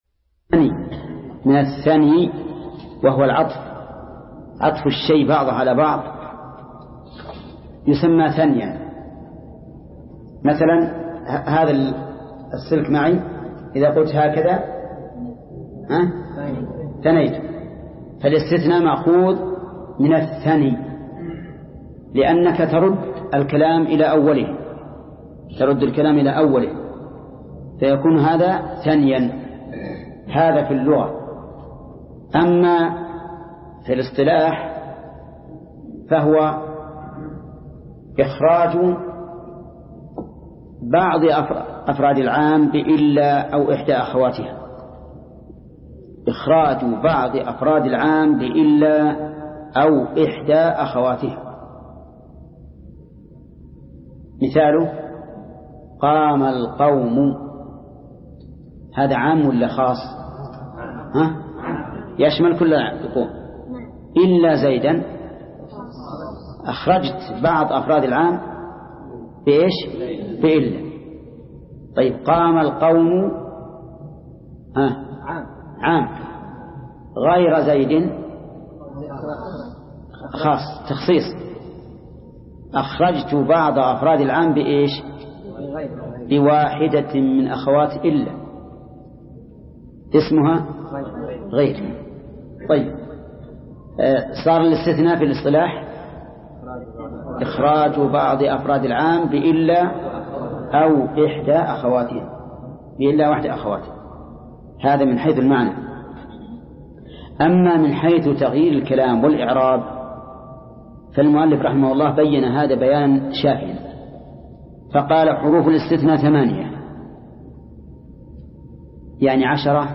درس (21) : شرح الآجرومية : من صفحة: (429)، قوله: (باب الاستثناء).، إلى صفحة: (456)، قوله: (تلخيص لأحكام الاستثناء).